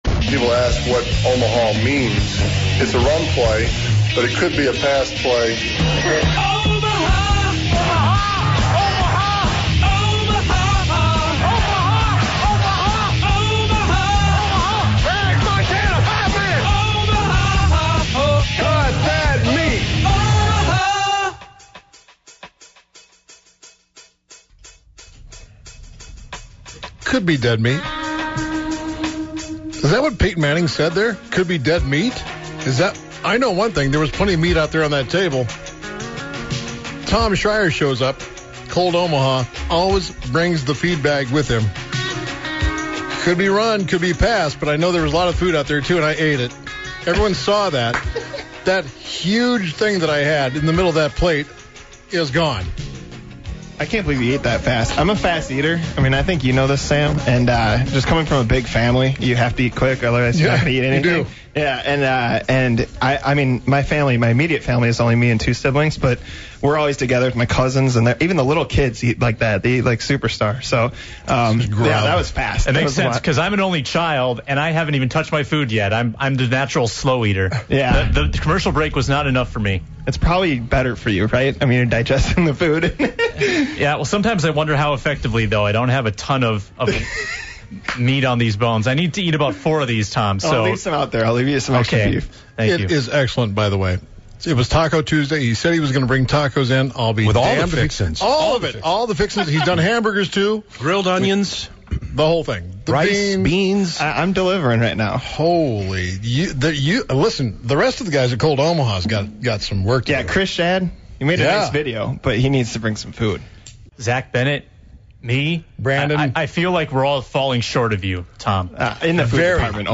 in studio to talk all the latest happenings with Cold Omaha and to cover the Adrian Peterson press conference. He sticks around to talk Minnesota Twins as well as more on the Vikings roster. The show wraps up with the Sillier Side of Sports and the True Superstar of the Day.